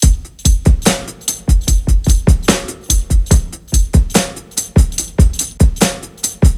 • 73 Bpm Drum Loop Sample G# Key.wav
Free drum loop - kick tuned to the G# note. Loudest frequency: 1709Hz
73-bpm-drum-loop-sample-g-sharp-key-iUl.wav